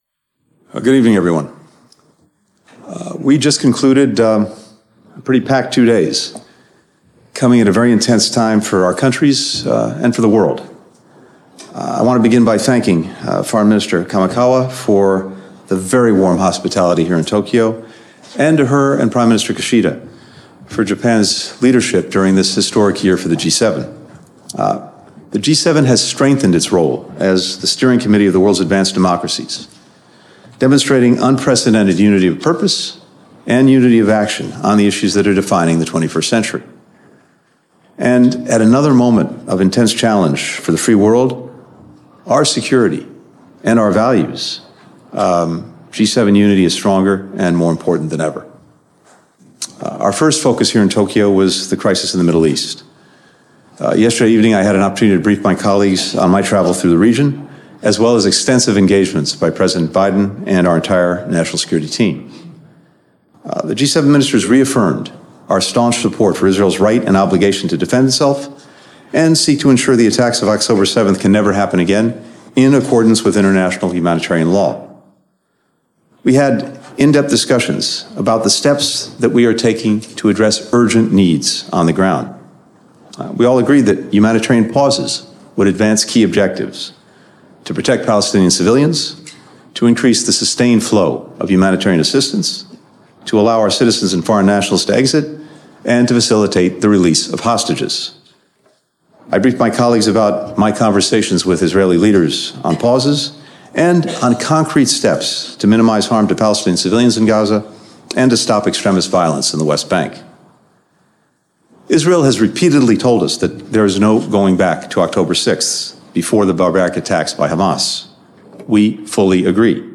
Antony Blinken - Tokyo Press Conference 2023 (transcript-audio-video)